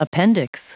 Prononciation du mot appendix en anglais (fichier audio)
Prononciation du mot : appendix